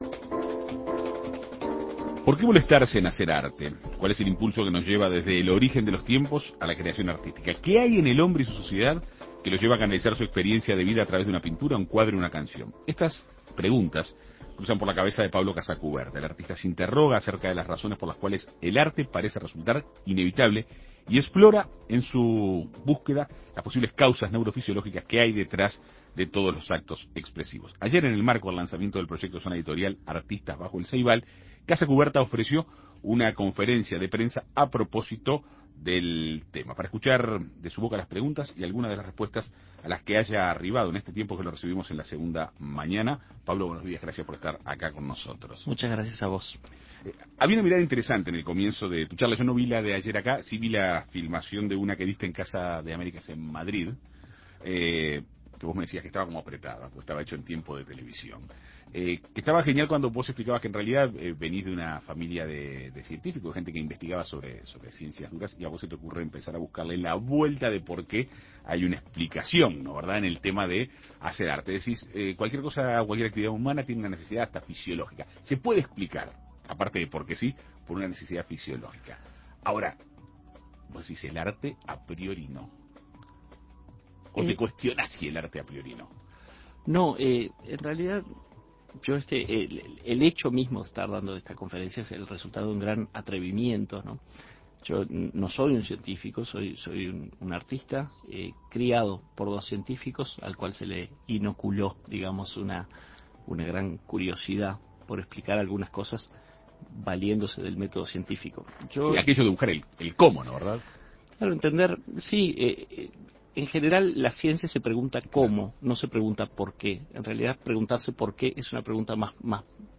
En Perspectiva Segunda Mañana dialogó con el artista.